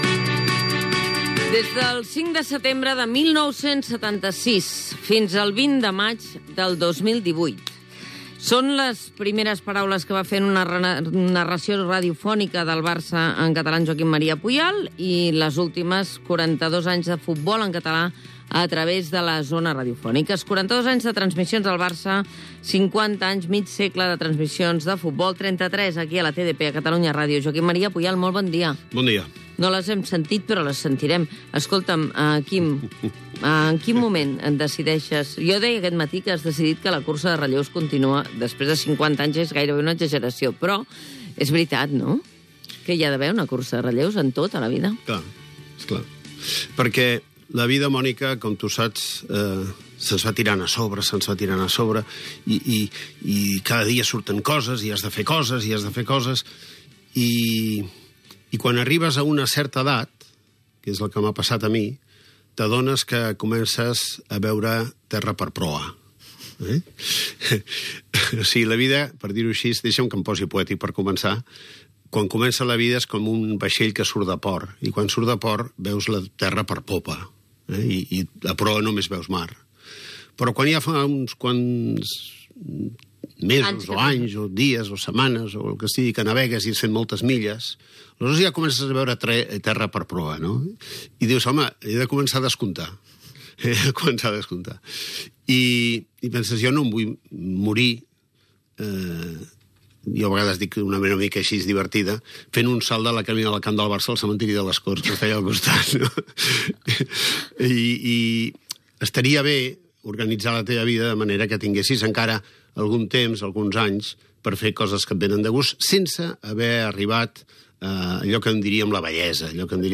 65b735820ffa77a862f69a49e4d45ebf4f8dc4d4.mp3 Títol Catalunya Ràdio Emissora Catalunya Ràdio Cadena Catalunya Ràdio Titularitat Pública nacional Nom programa El matí de Catalunya Ràdio Descripció Entrevista a Joaquim Maria Puyal quan deixa les transmissions de futbol en català a la ràdio després d'haver-les fet durant 42 anys i haver estat 50 anys treballant a la ràdio. Indicatiu del programa.